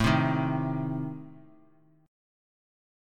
Adim chord